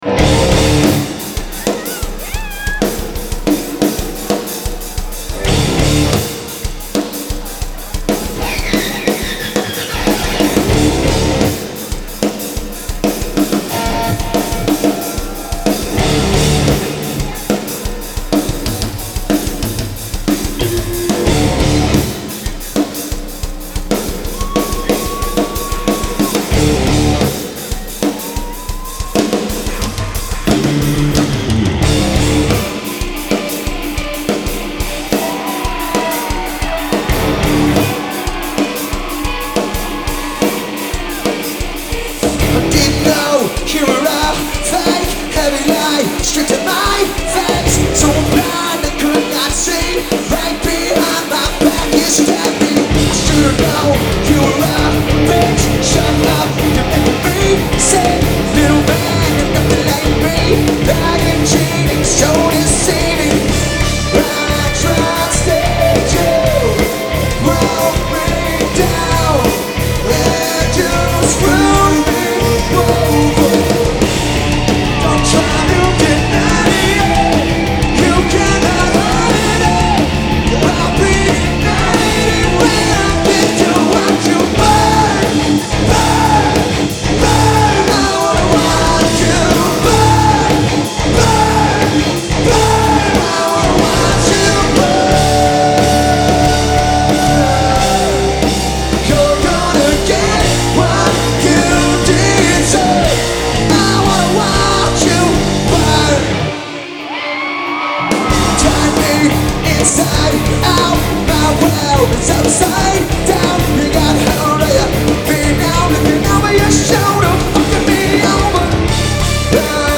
Genre : Alternative Rock
Live At Fillmore Detroit